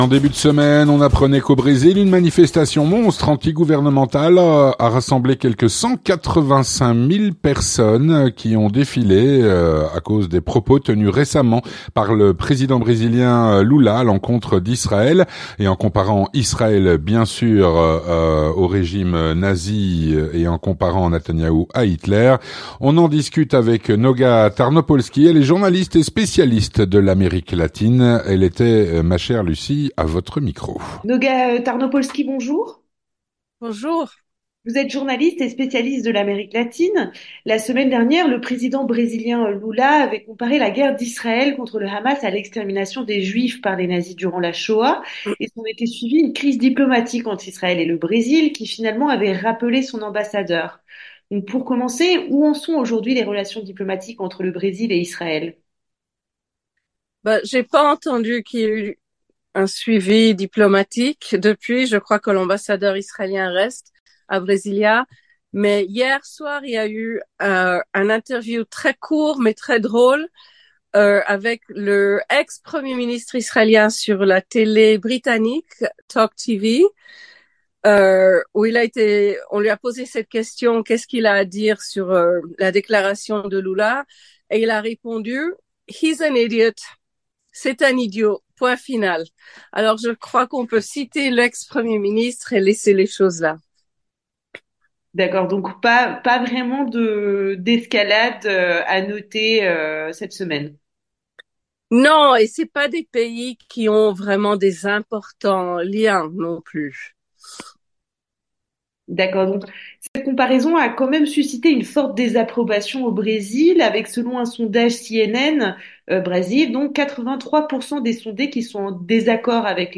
journaliste et spécialiste de l’Amérique latine.